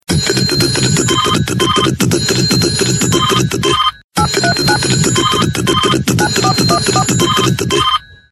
Категория: Рингтоны приколы